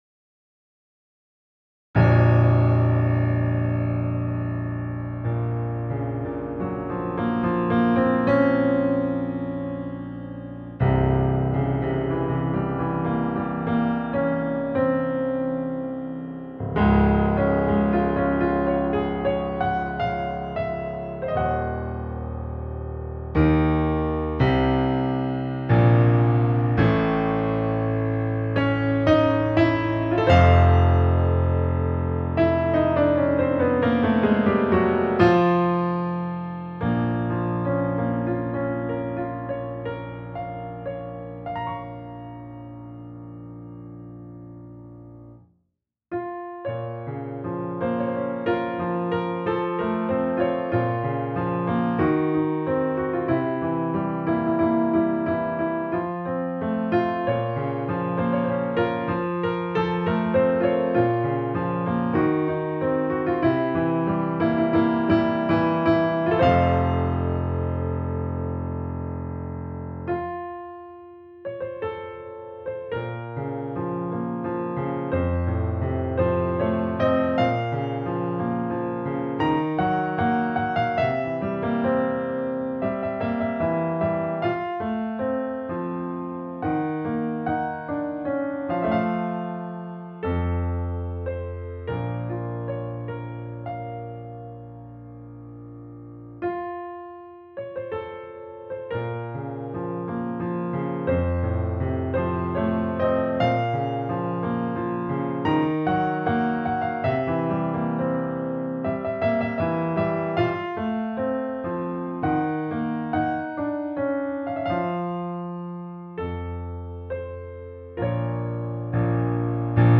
at Shakespeare House
A Performance That Transcended Science